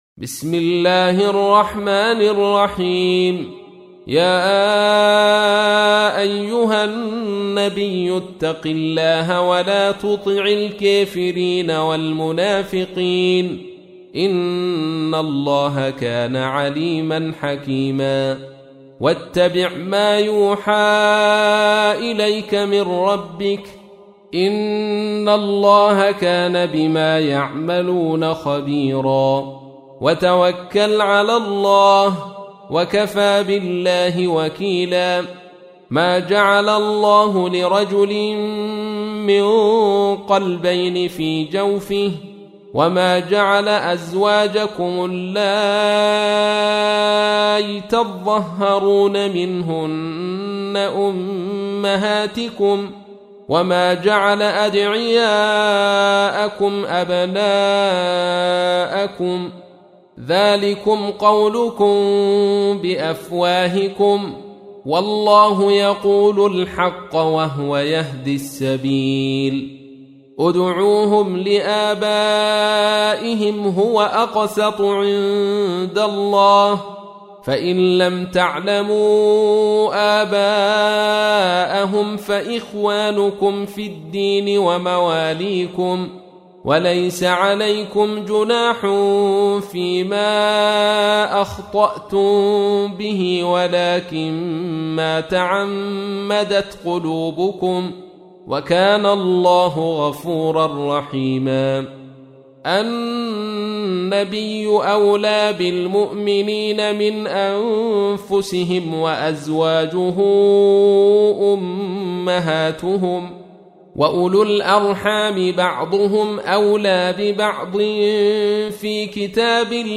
تحميل : 33. سورة الأحزاب / القارئ عبد الرشيد صوفي / القرآن الكريم / موقع يا حسين